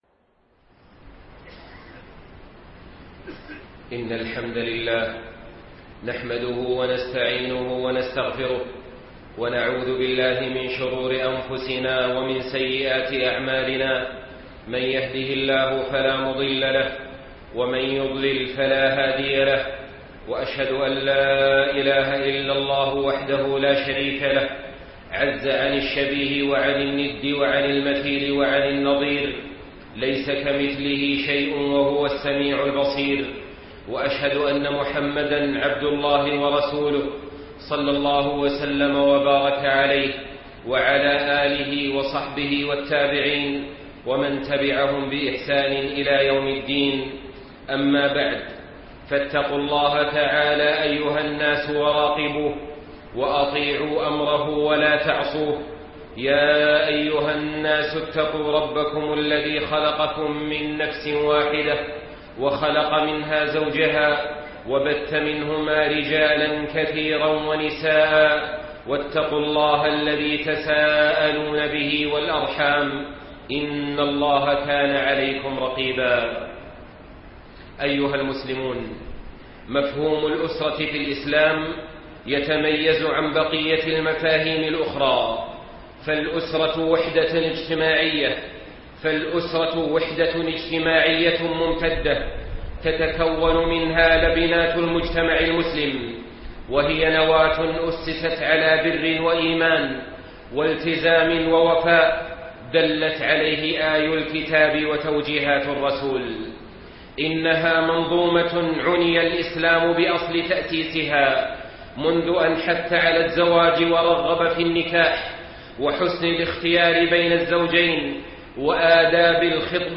مفهوم الأسرة في الإسلام( 6/6/2014)خطب الجمعة من مكة المكرمة - الشيخ صالح آل طالب